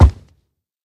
1.21.4 / assets / minecraft / sounds / mob / hoglin / step5.ogg
step5.ogg